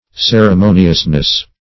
Search Result for " ceremoniousness" : Wordnet 3.0 NOUN (1) 1. a ceremonial manner ; The Collaborative International Dictionary of English v.0.48: Ceremoniousness \Cer`e*mo"ni*ous*ness\, n. The quality, or practice, of being ceremonious.
ceremoniousness.mp3